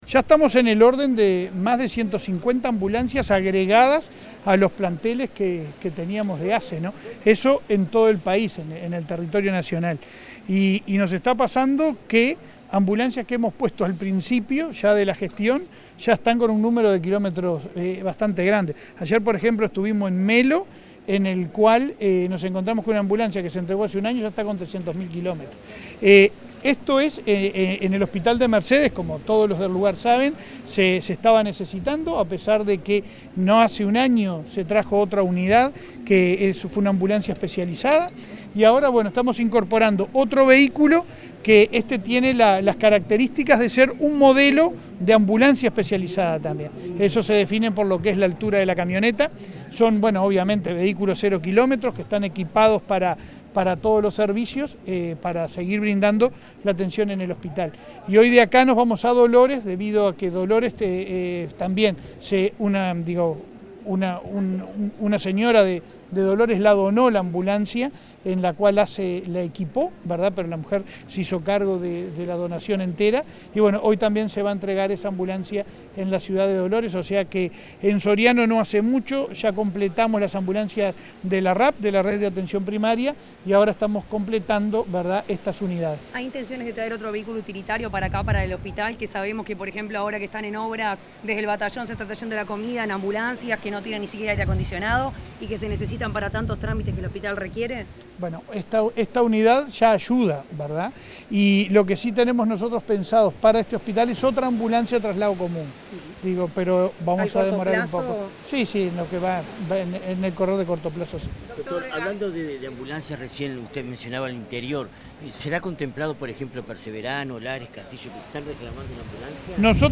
Declaraciones a la prensa de autoridades de ASSE